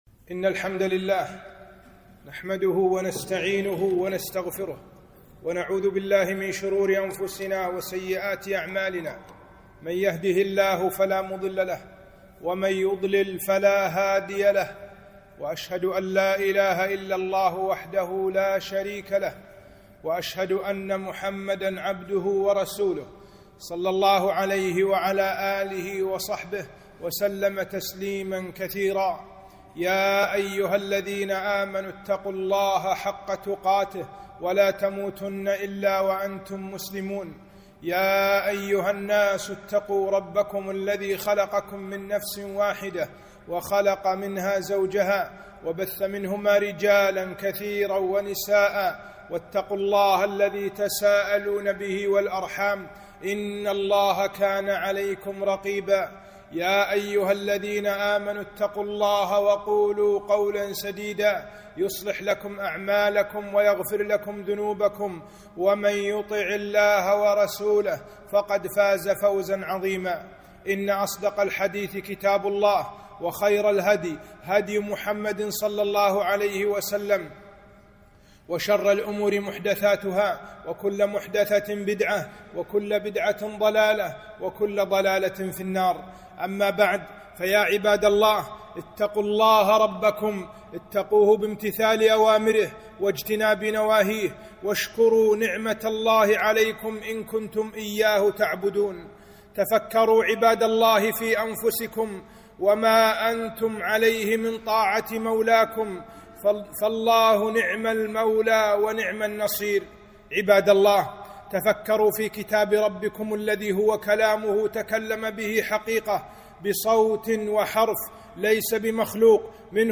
خطبة - نعمة الهداية شكرها وبيان أسبابها